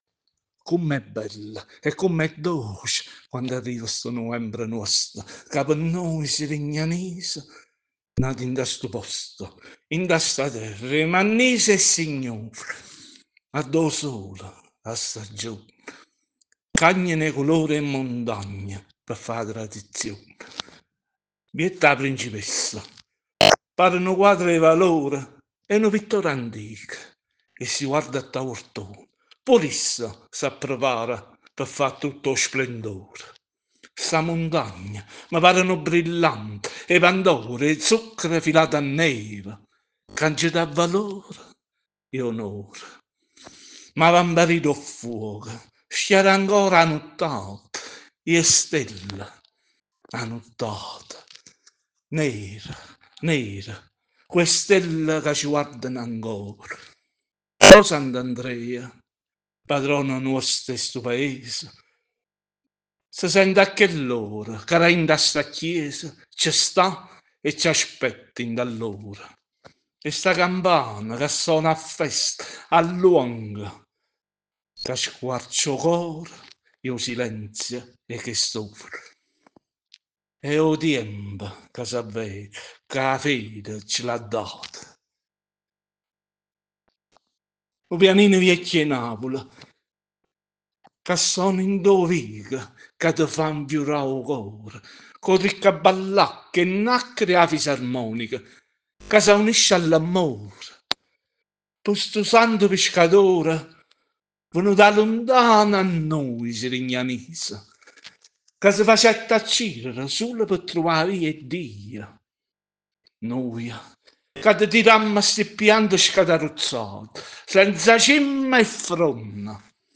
Novembre, il mese che apre il cuore di Sirignano: un canto di fede in vernacolo napoletano
In occasione dell’apertura del mese di Novembre, mese storicamente significativo per la nostra comunità e preludio all’attesa del “Natske Piccirillo” (il nostro Natale Piccolino), proponiamo un canto di fede in vernacolo napoletano che celebra l’autentica devozione di Sirignano.